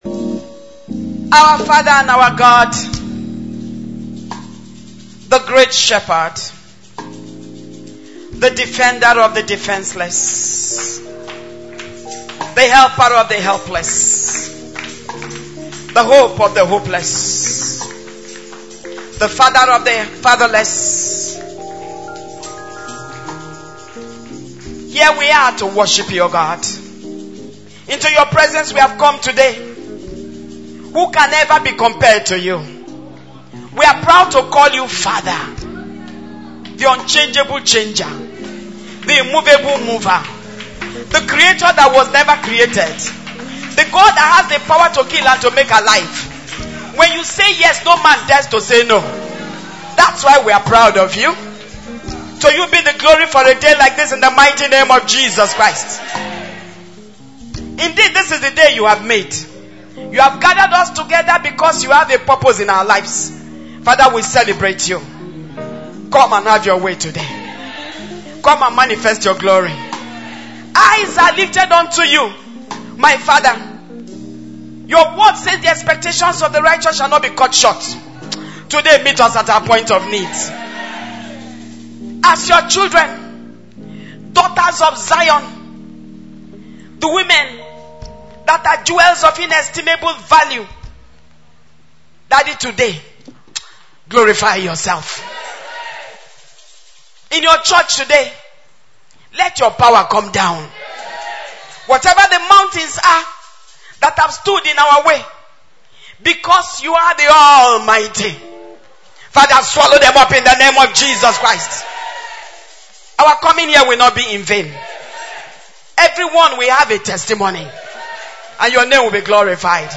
is the Sisters’ Conference 2015 Sunday Sermon